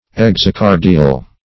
Search Result for " exocardial" : The Collaborative International Dictionary of English v.0.48: Exocardiac \Ex`o*car"di*ac\, Exocardial \Ex`o*car"di*al\, a. [Exo- + Gr. kardi`a heart.]